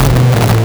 Player_Glitch [90].wav